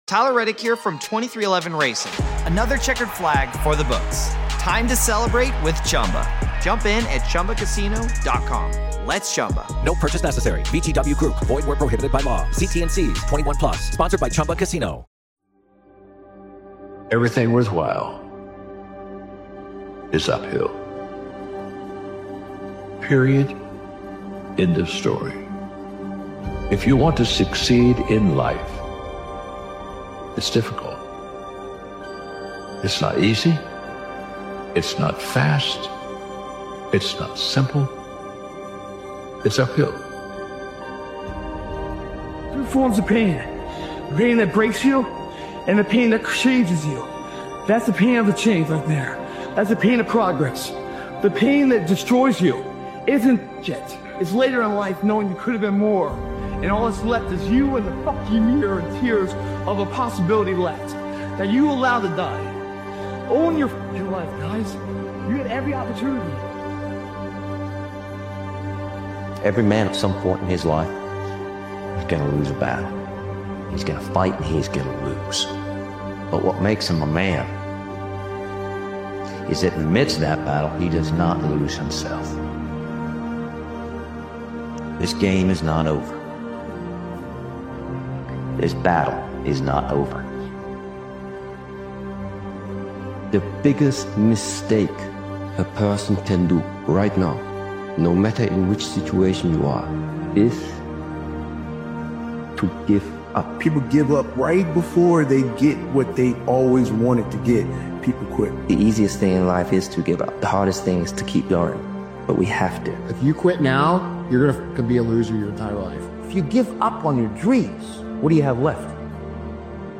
Powerful Motivational Speech is an emotional and grounding motivational speech created and edited by Absolute Motivation. This powerful motivational speech compilation is a reminder that just because things feel heavy right now doesn’t mean your story is over.